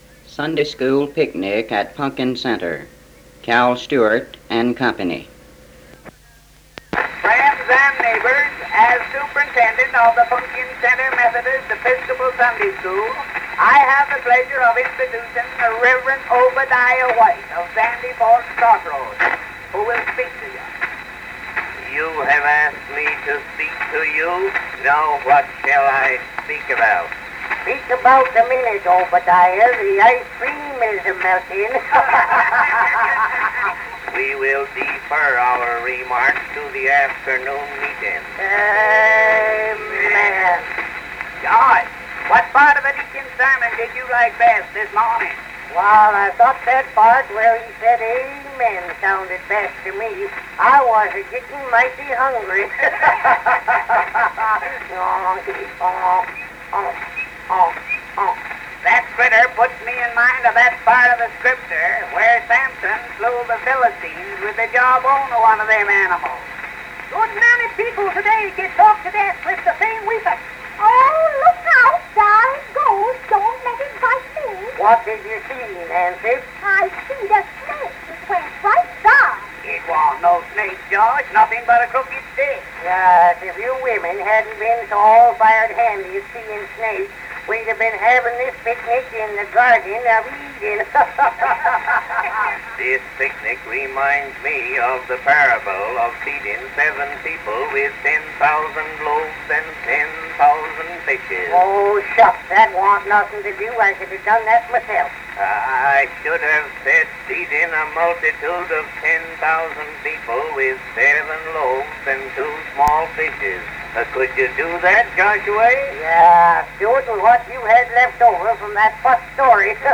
The comedy routine, Sunday school picnic at Punkin Center. Featuring Cal Stewart and Company.